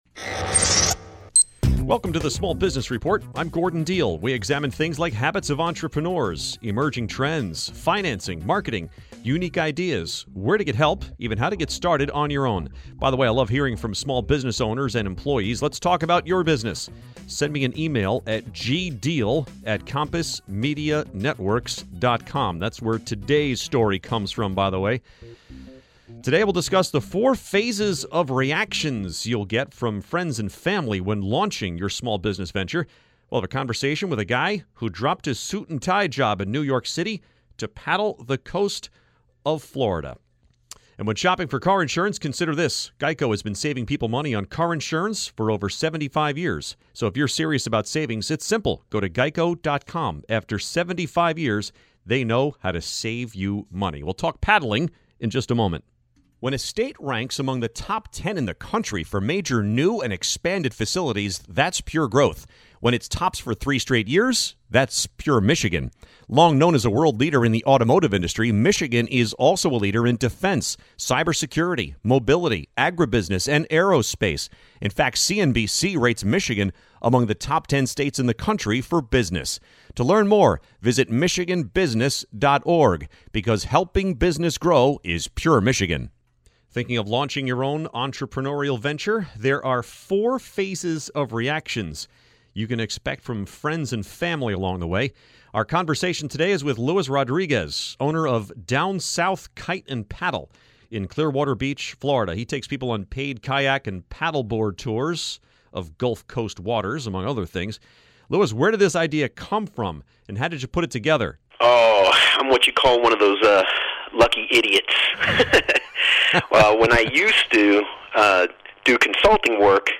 The four phases of reactions you'll get from friends and family when launching your small business venture. A conversation with a guy who dropped his suit and tie job in New York City to paddle the coast of Florida.